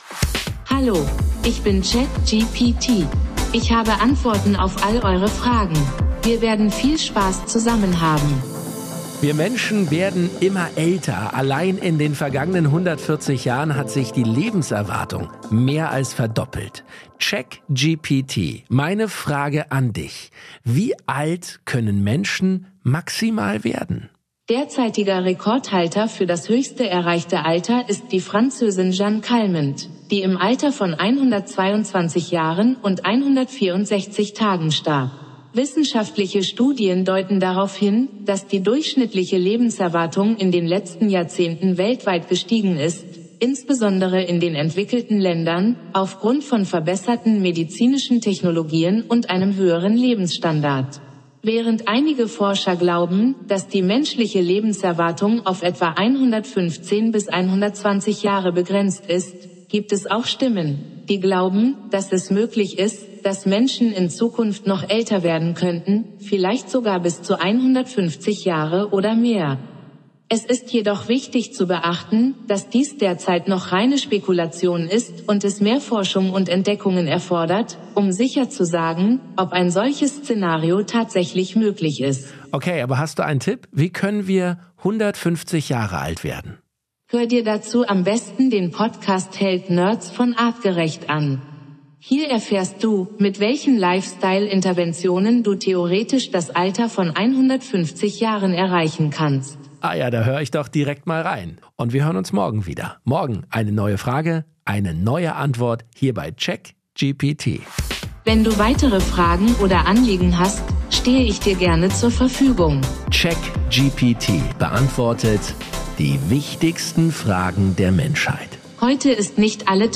Intelligenz ChatGPT von OpenAI als Co-Host.